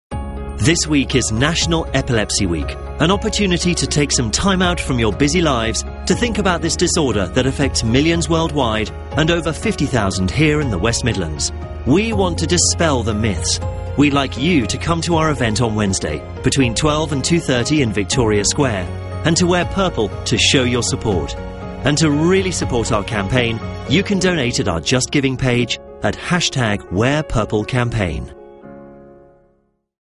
Radio Adverts
Heart-FM-Radio-Advert-1.mp3